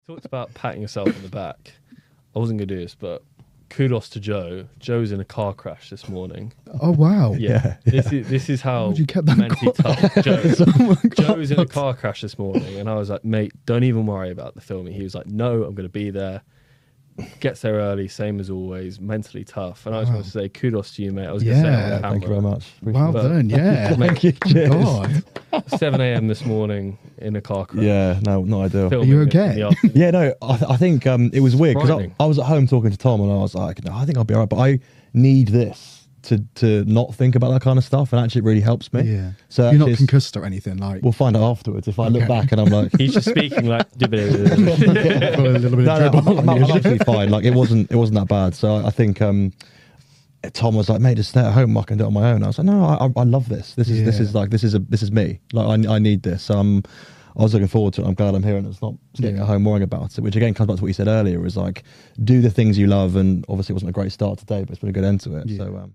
One of the toughest men you could come across who was struggling through the recording.